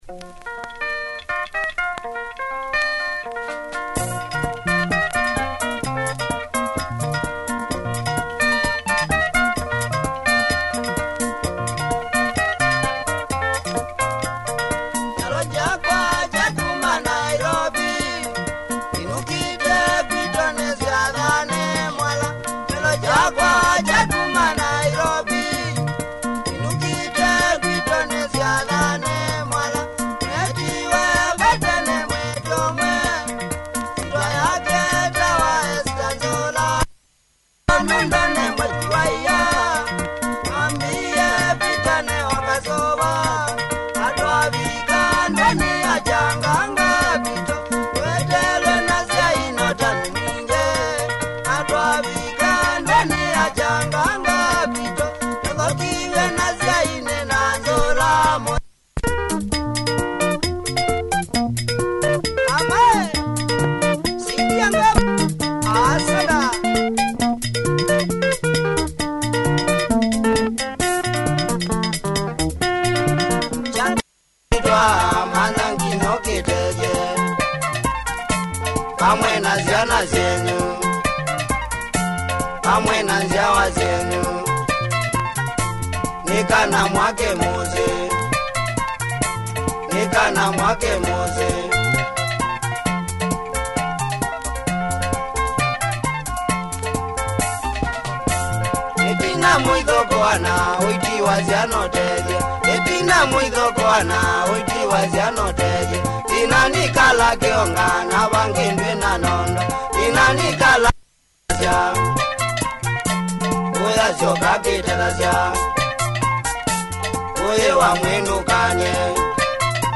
Punchy Kamba Benga
nice breakdown